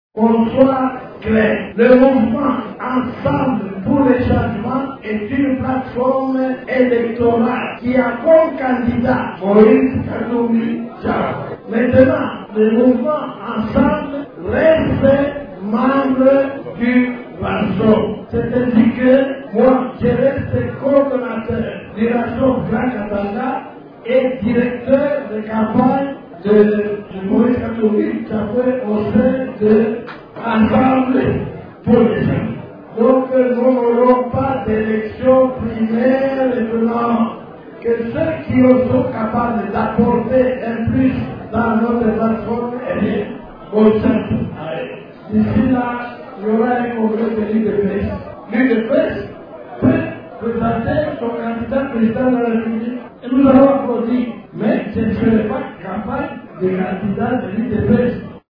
Kyungu wa Kumwanza a fait cette déclaration au cours d’une conférence de presse, organisée en marge de la séance de restitution des  travaux du conclave de Johannesburg.